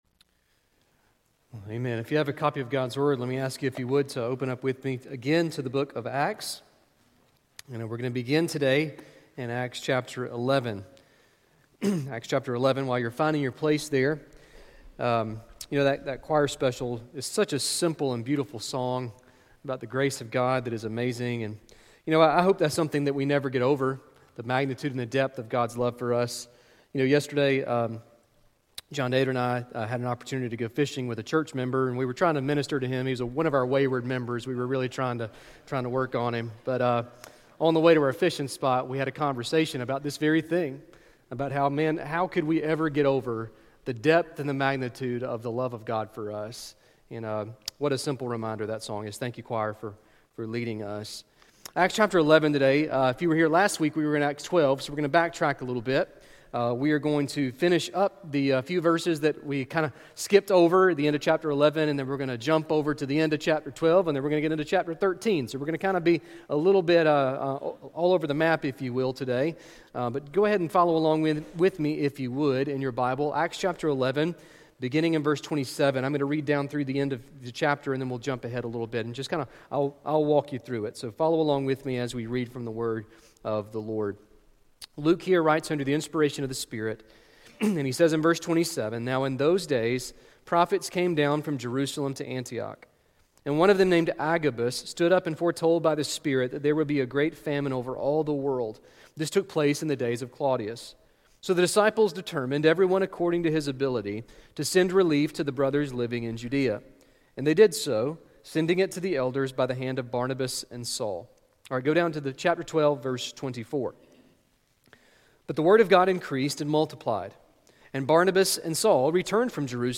Sermon Listen Service Scripture References